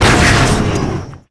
acannonaltfire02.wav